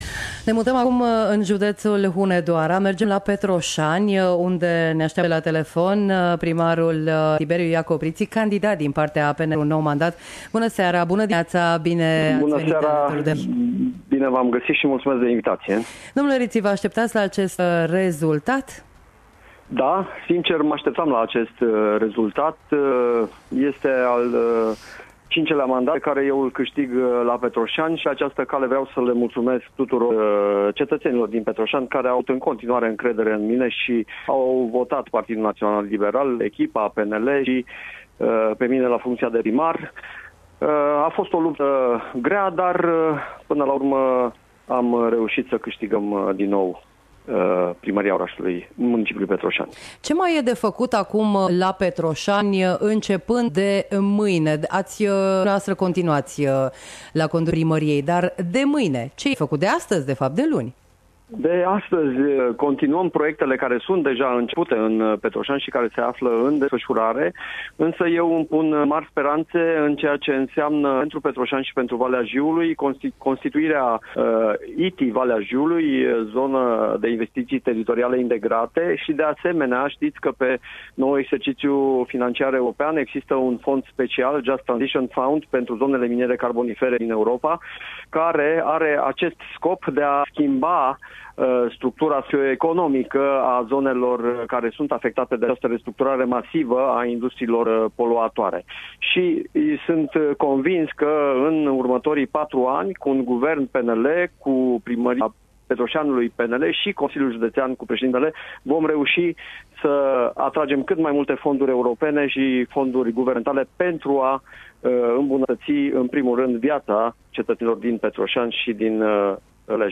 Ascultați integral dialogul pe care l-a avut cu realizatorii emisiunii Noaptea Albă a Alegerilor de la Radio Timișoara.